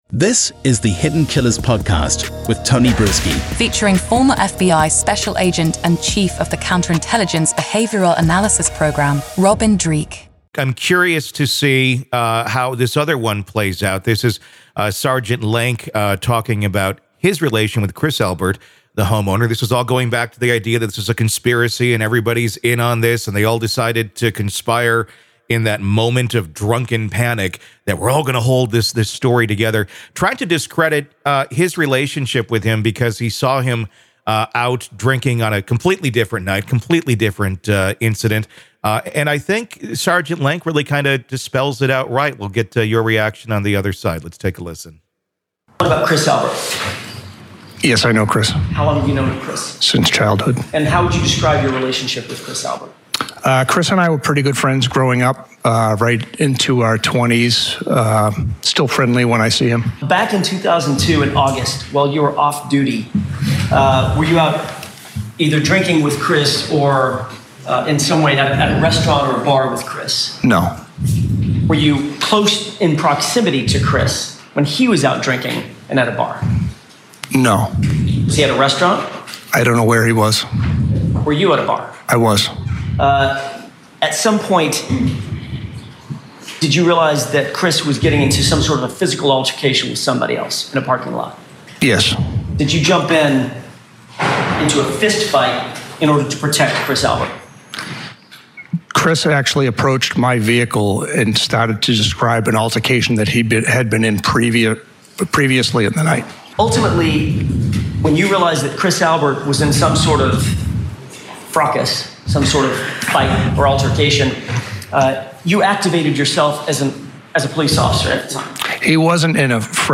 Main Points of the Conversation